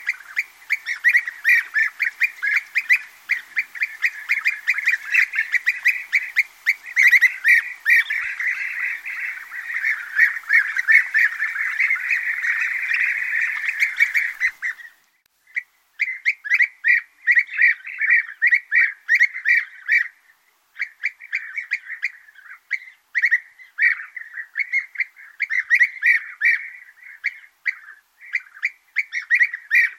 Guêpier d'Europe - Mes zoazos
guepier-d-europe.mp3